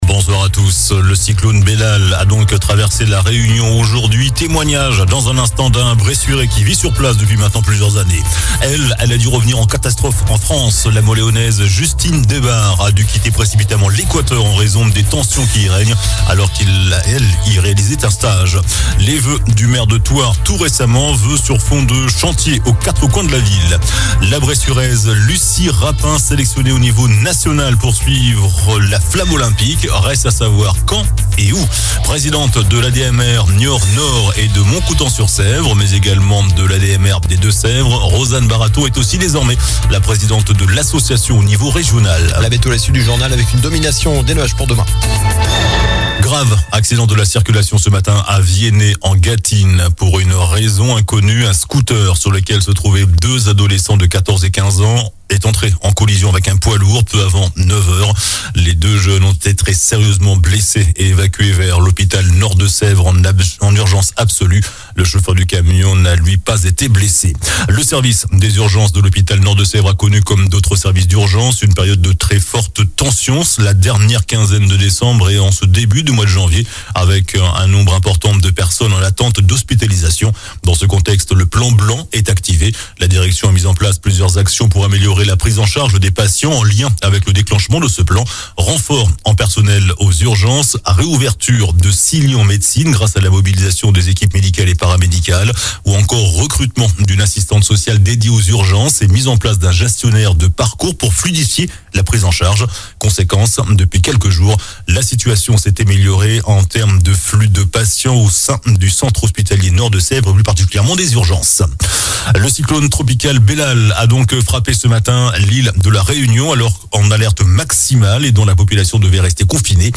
Journal du lundi 15 janvier (soir)
infos locales